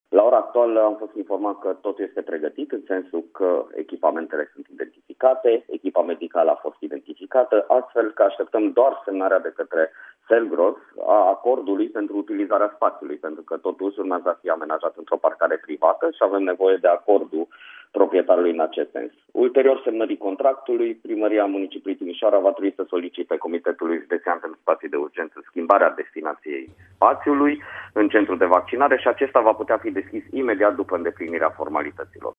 Este vorba despre localităţi unde nu există centre de vaccinare, a precizat, la Radio Timişoara, prefectul Zoltan Nemeth.
Zoltan-Nemeth-vaccinare-2.mp3